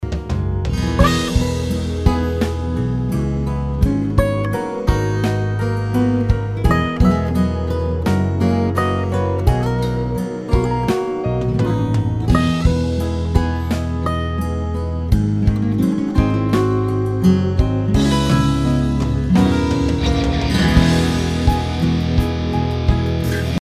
P87 电容话筒
我们模拟了Vintage的设计特性，成功捕捉到Vintage U87魔法般的音色。